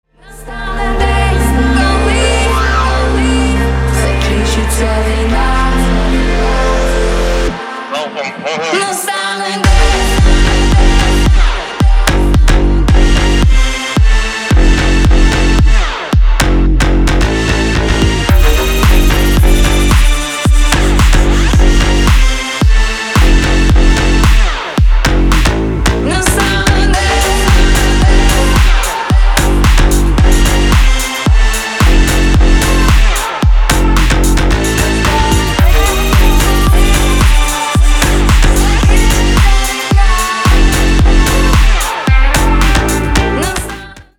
• Качество: 320, Stereo
громкие
женский голос
Electronic
басы
house